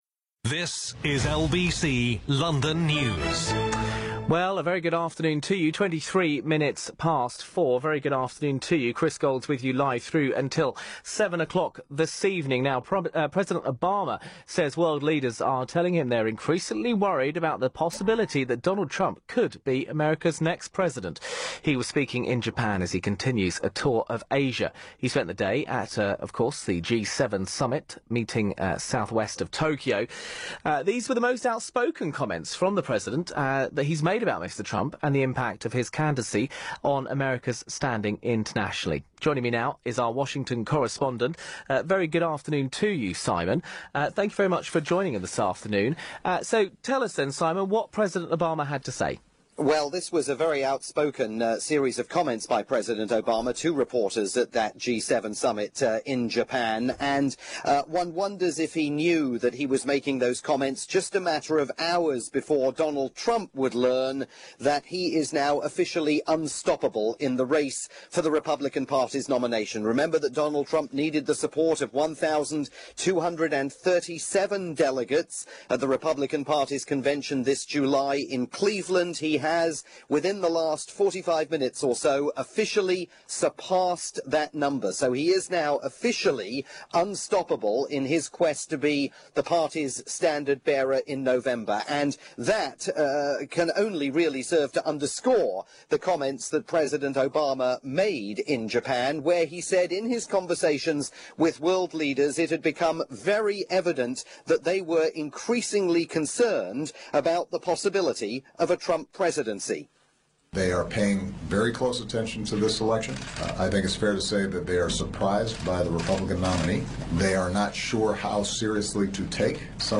live coverage from rolling news station